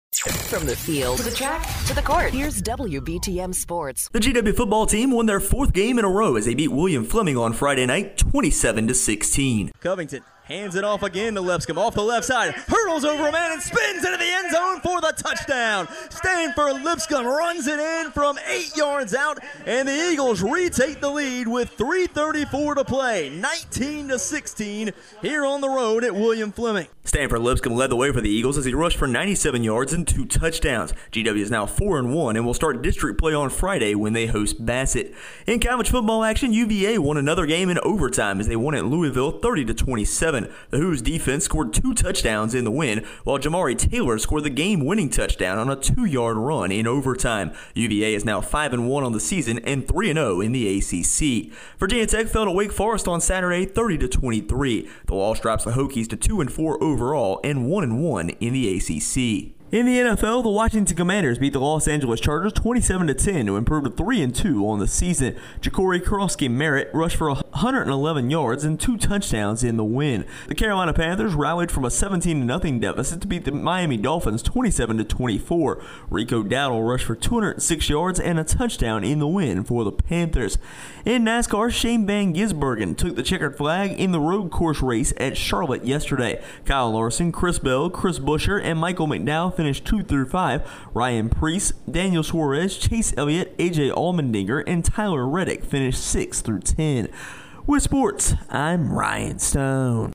GW Football Wins 4th in a Row, Commanders Beat Chargers and More in Our Local Sports Report